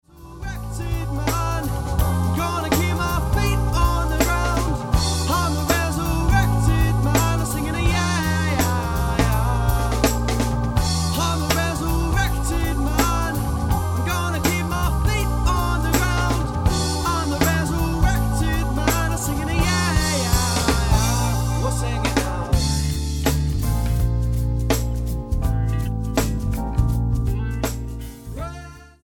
STYLE: Jazz
is a delicious lugubrious groove